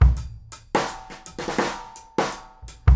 GROOVE 180HL.wav